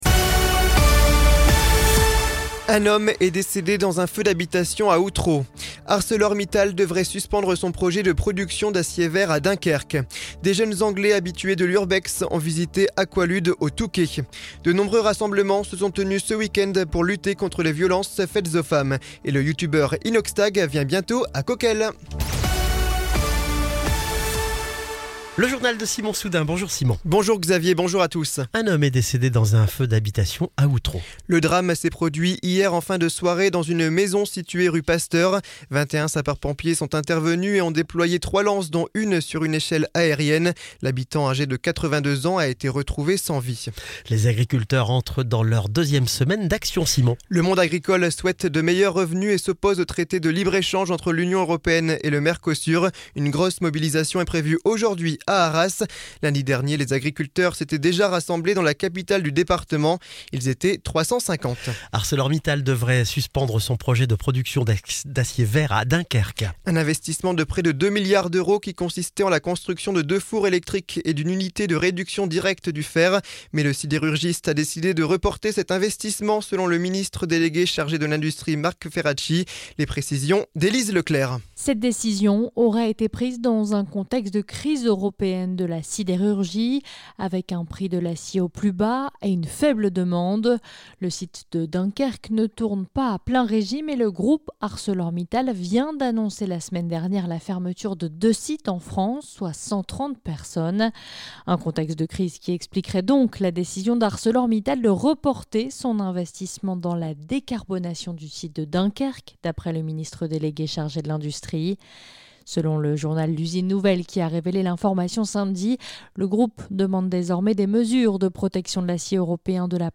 Le journal de ce lundi 25 novembre 2024